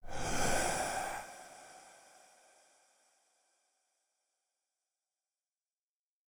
rasping-whispers-001.ogg